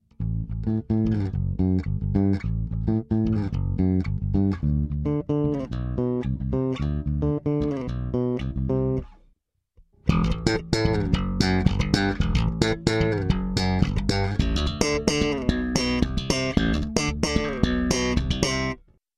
In this example a funk octave riff is played finger style, and then using slap techniques and “hammer-ons”:
Finger Then Slap
If you look at the waveform of the above sample (for example, using the free audio editor Audacity), you will see that the slap style is louder than regular finger style, and it has very loud peaks like a percussion instrument.
bt3_3_Electric_Bass_Finger_Then_Slap.mp3